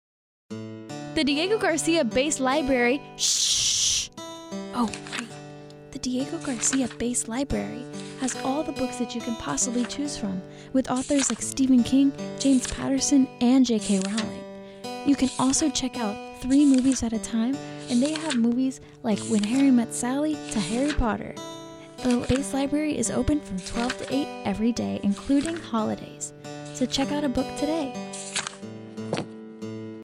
Base LibraryNSFDiegoGarciaRadioSpotAFN